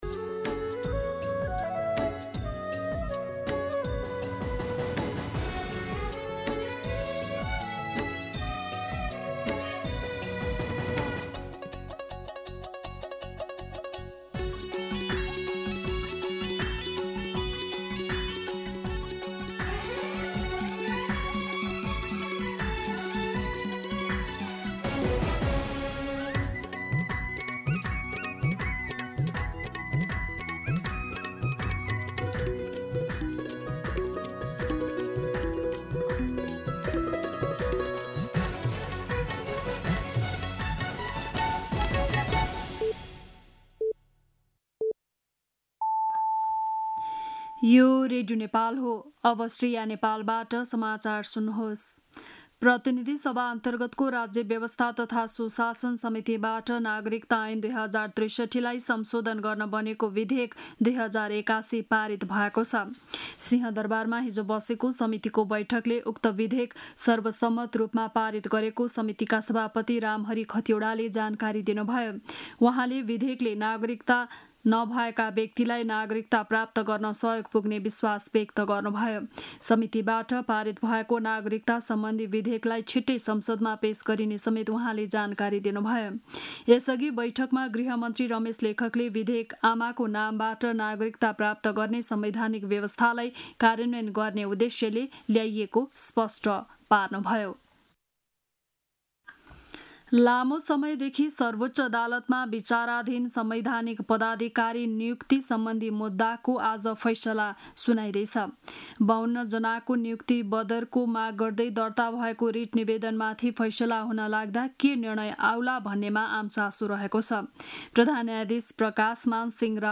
An online outlet of Nepal's national radio broadcaster
बिहान ११ बजेको नेपाली समाचार : २८ जेठ , २०८२